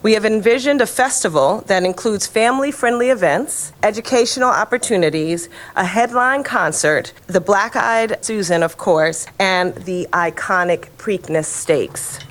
Preakness Festival 150 plans were rolled out at a Camden Yards press conference Wednesday afternoon.